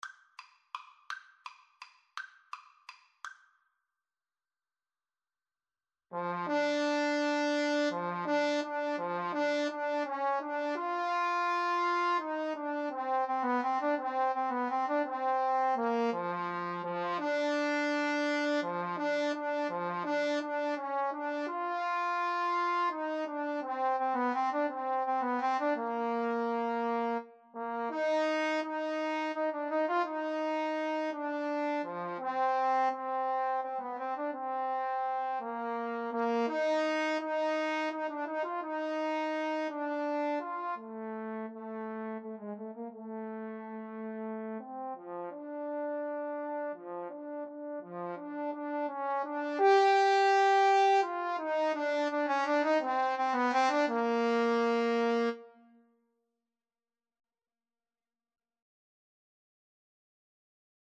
3/4 (View more 3/4 Music)
Tempo di valse =168
Classical (View more Classical Trombone Duet Music)